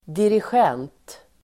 Uttal: [dirisj'en:t el. -g'en:t]